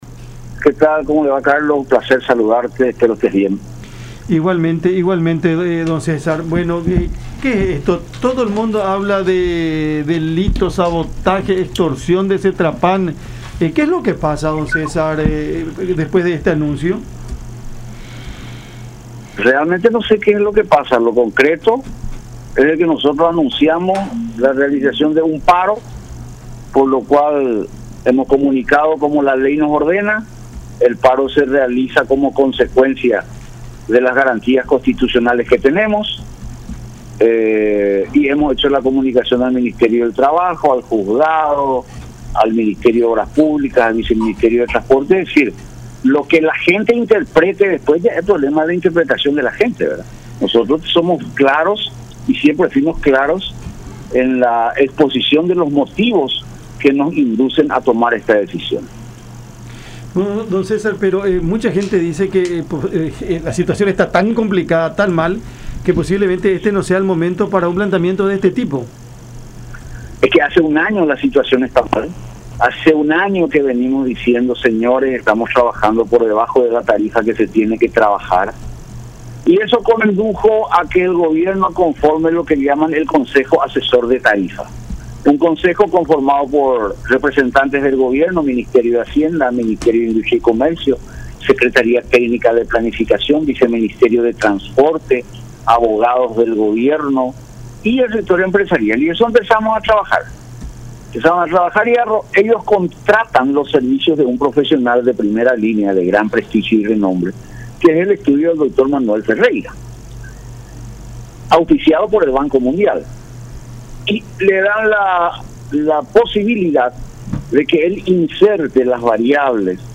en diálogo con Cada Mañana por La Unión